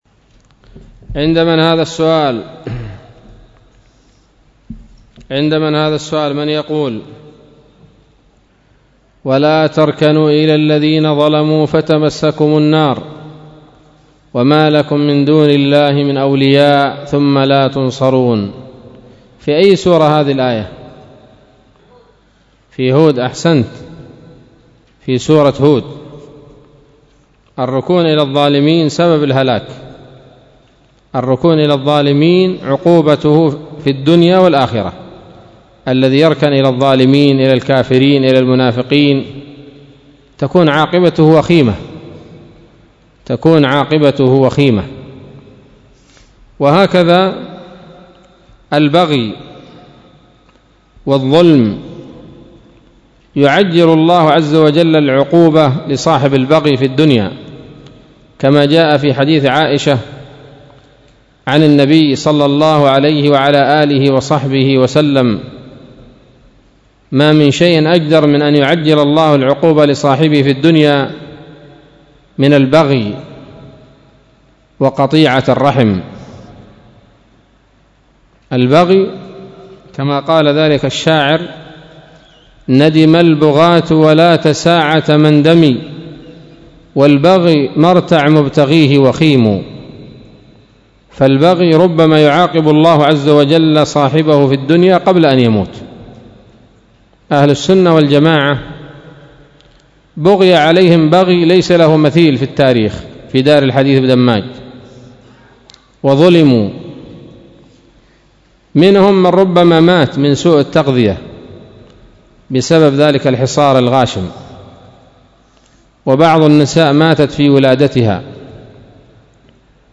كلمة بعنوان